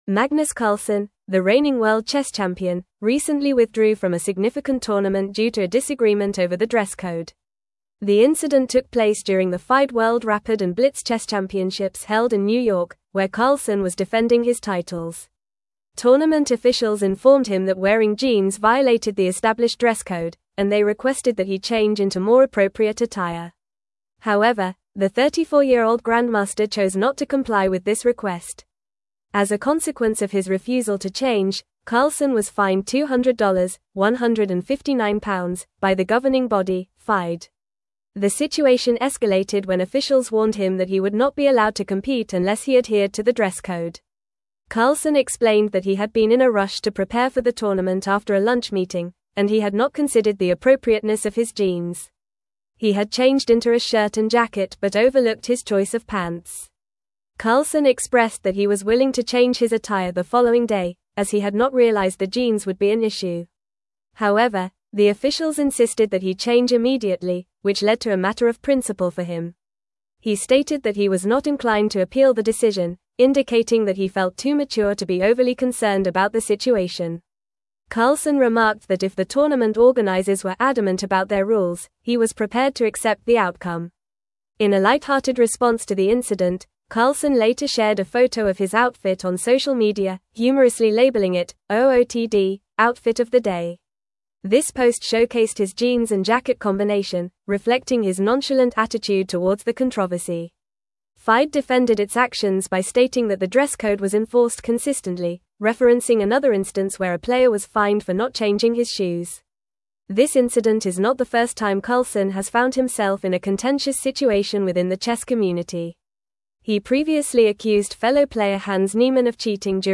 Fast
English-Newsroom-Advanced-FAST-Reading-Carlsen-Withdraws-from-Tournament-Over-Dress-Code-Dispute.mp3